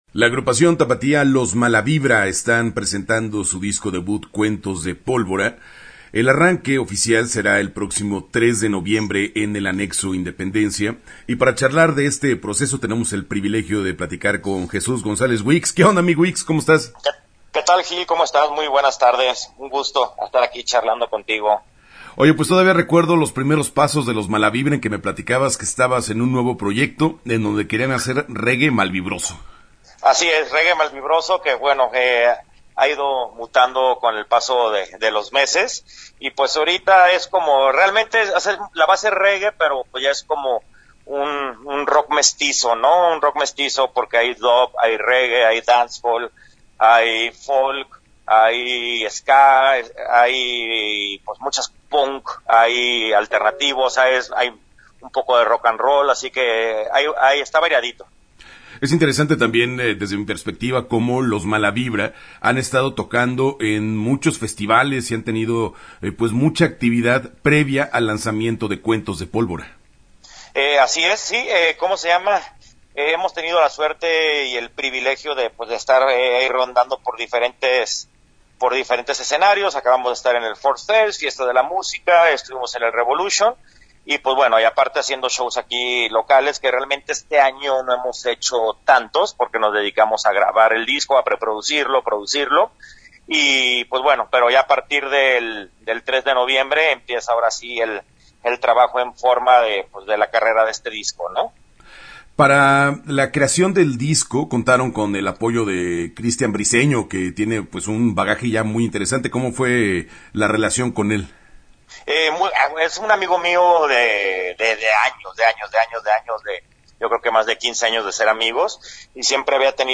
Entrevista-Los-Malavibra-2018-web.mp3